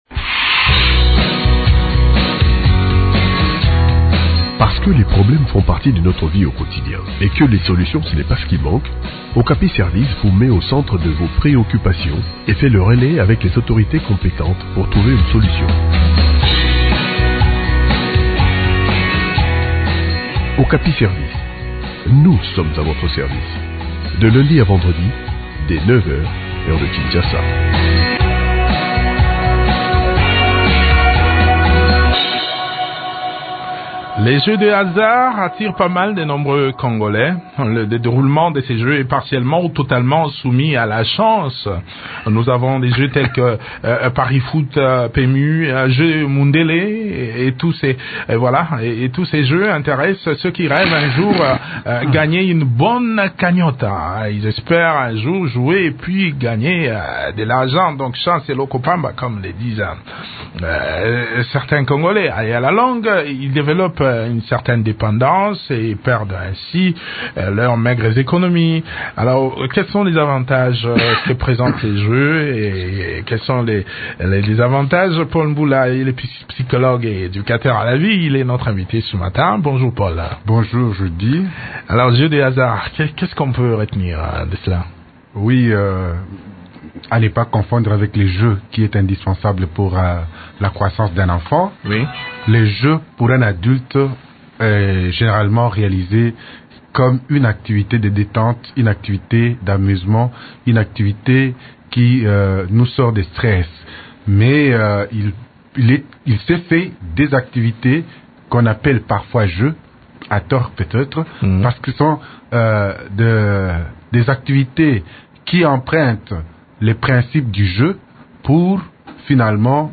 psychologue et éducateur à la vie.